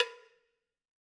Cowbell1_Hit_v3_rr2_Sum.wav